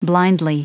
Prononciation du mot blindly en anglais (fichier audio)
Prononciation du mot : blindly
blindly.wav